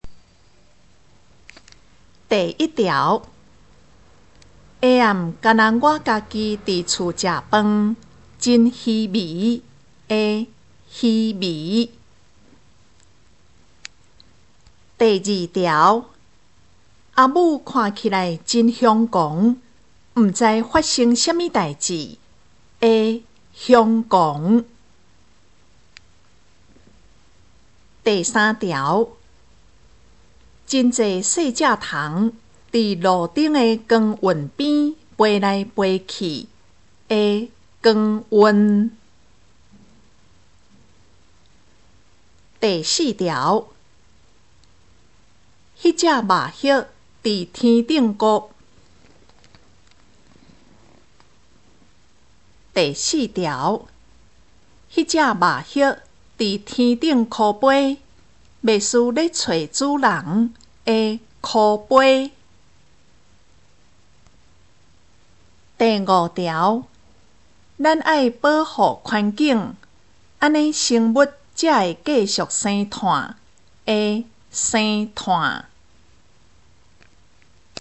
【國中閩南語4】每課評量(4)聽力測驗mp3